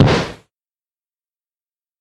Звуки кулаков
На этой странице собрана коллекция звуков кулаков — резкие удары, глухие толчки, звонкие столкновения.
Игровой вариант